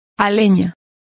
Complete with pronunciation of the translation of privets.